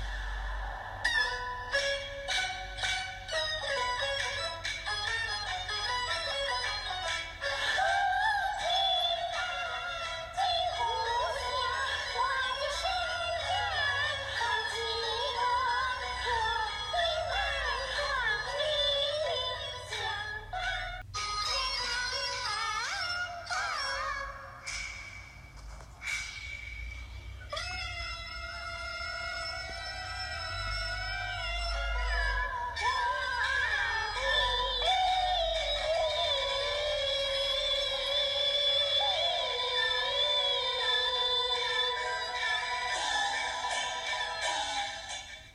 대체로 하이톤에 쨍쨍한 소리로 어떤 배우가 불러도 비슷하게 들리기 때문에 개개인의 차이를 확인하기 까다롭다.
두 번째 대결은 패널들이 선택한 세 명의 배우가 함께 합창했고(나머지 8명은 마이크를 꺼놓음) 반주도 더해져 난도가 높아졌다. 우리도 세 배우의 합창을 한 번 들어보자.
아마 우리 귀에는 복잡하게 들려 어느 한 명의 목소리 특징을 유추해내기 힘들었을 것이다.